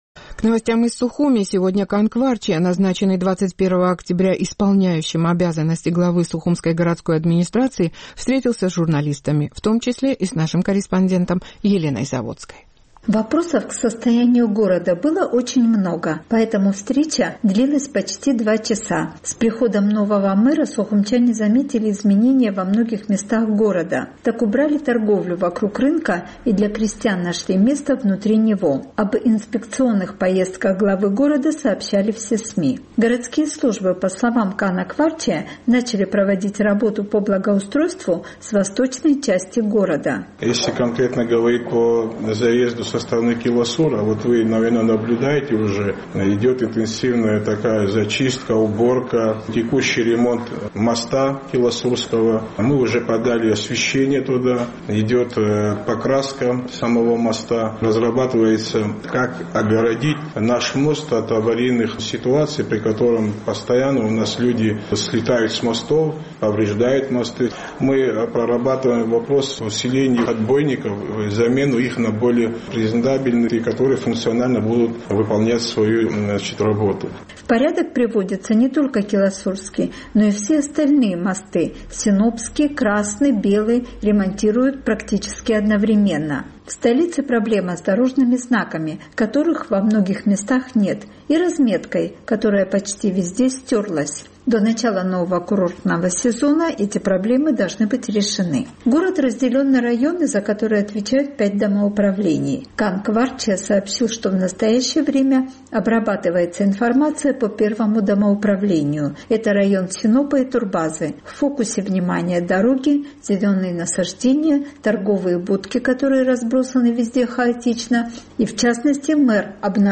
Назначенный 21 октября этого года исполняющим обязанности главы администрации г. Сухума Кан Кварчия встретился с журналистами и ответил на вопросы о состоянии города и перспективах наведения порядка в столице.